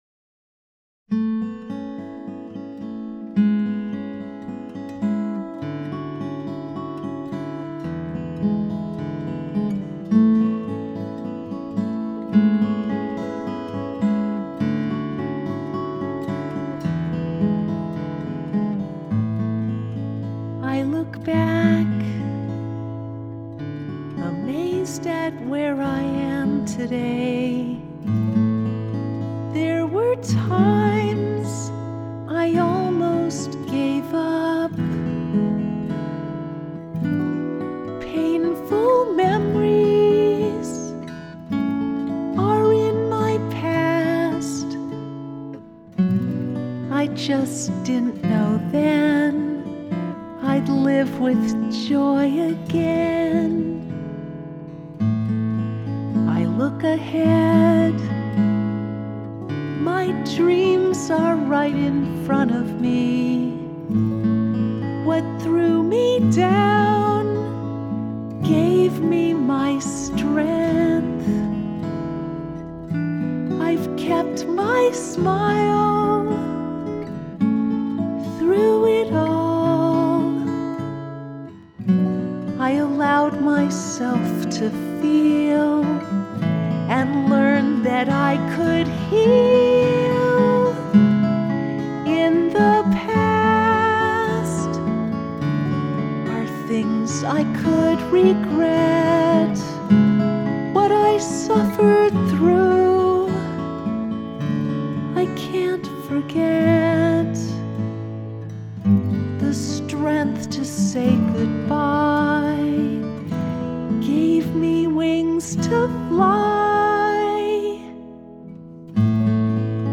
in-the-past-acoustic-5-16-18.mp3